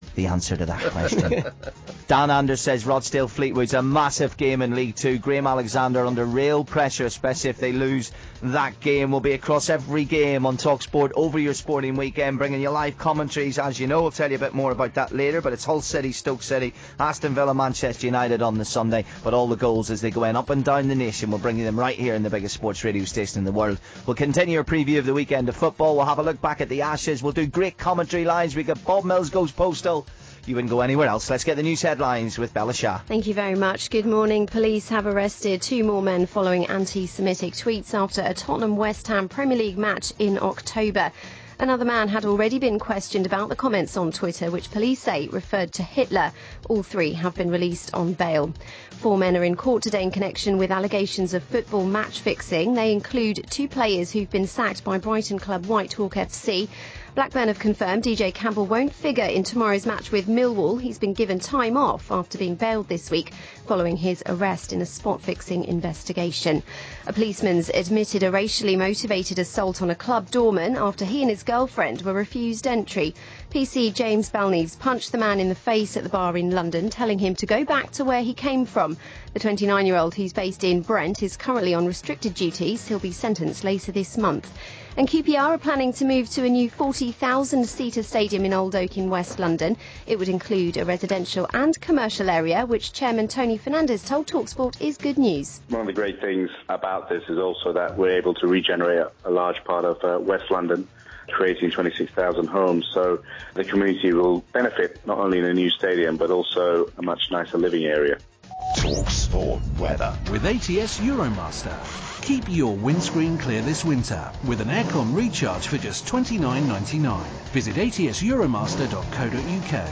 During Colin Murray's late morning show on talkSPORT radio the MASFL got a reviewing by Colin and co. with the Hurricanes one of the teams mentioned.
talksport_colin_murray_masfl.mp3